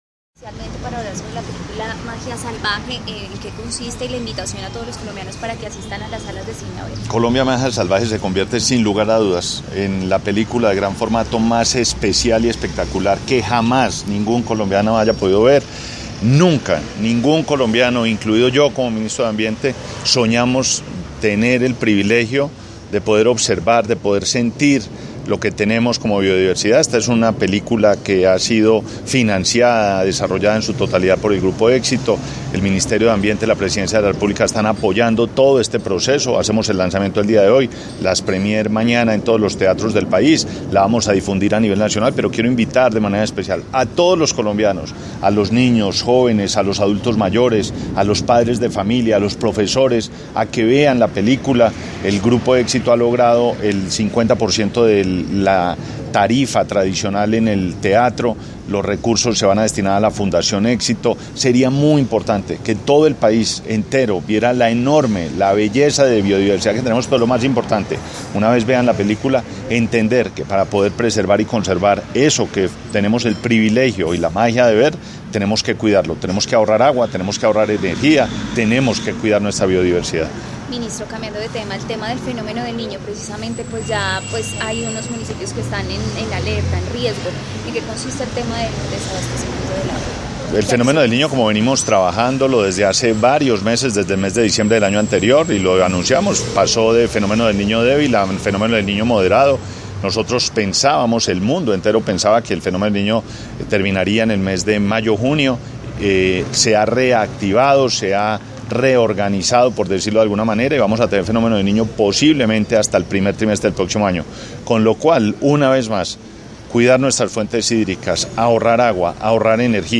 Declaraciones del Ministro de Ambiente y Desarrollo Sostenible, Gabriel Vallejo López audio